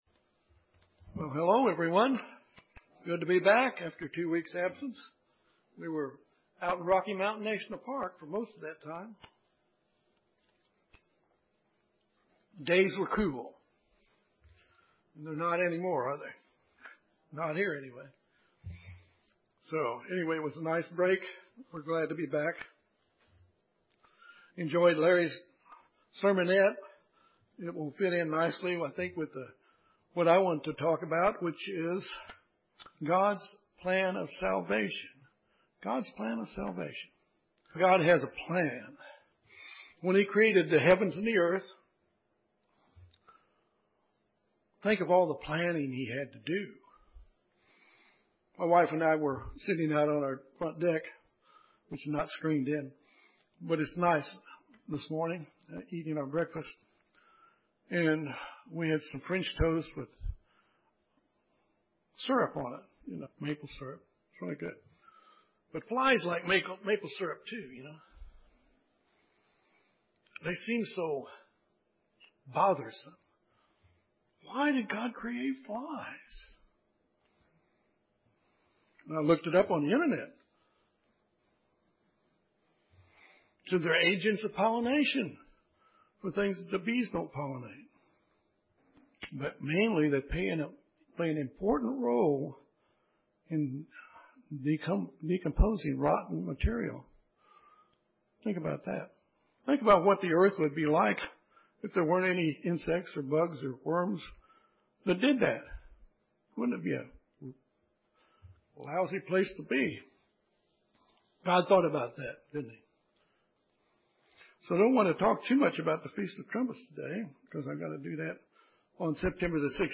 God’s plan of salvation is misunderstood by most professing churches. This sermon examines how God’s plan for mankind is revealed through His holy days.